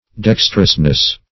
dextrousness - definition of dextrousness - synonyms, pronunciation, spelling from Free Dictionary
Dextrousness \Dex"trous*ness\, n.